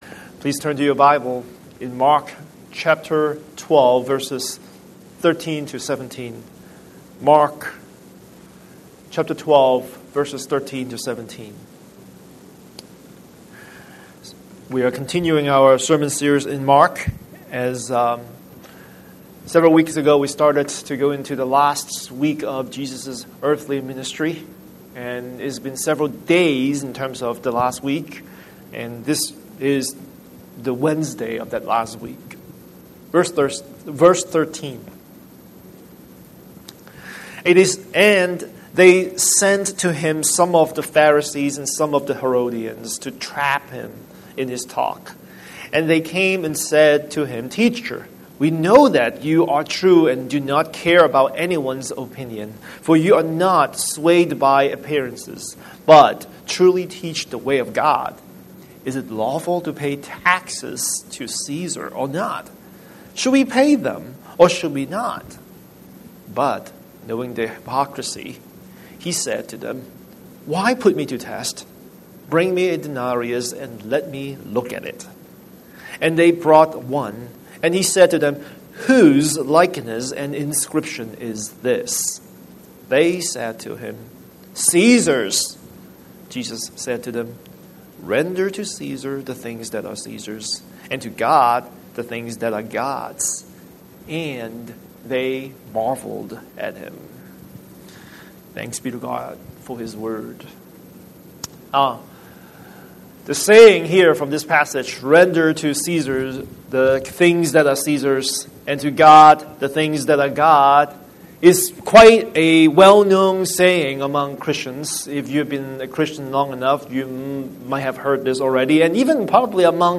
Scripture: Mark 12:13-17 Series: Sunday Sermon